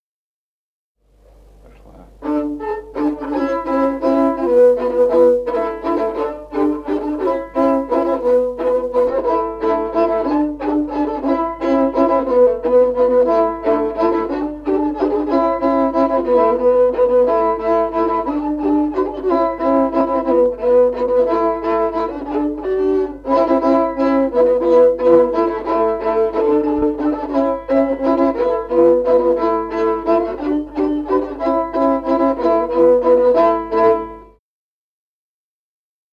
Музыкальный фольклор села Мишковка «Кручена», партия 2-й скрипки.